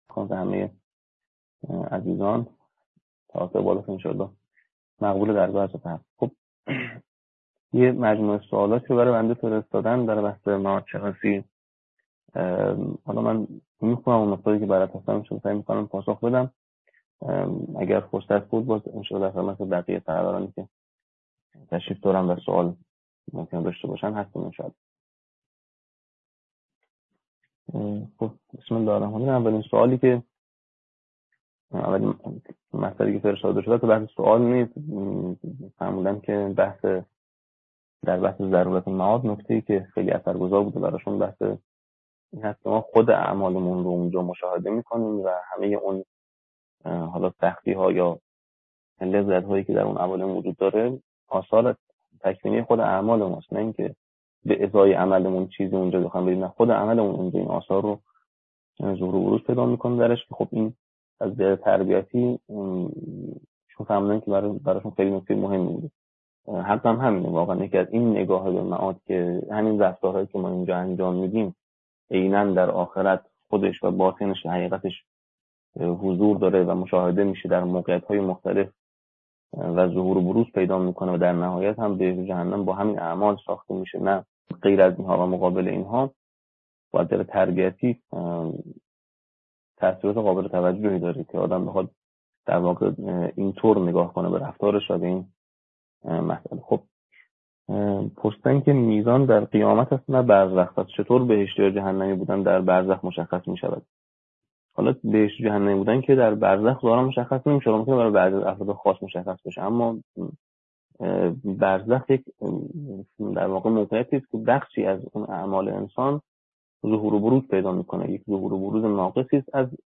فرجام شناسی - جلسه-پرسش-و-پاسخ